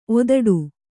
♪ odaḍu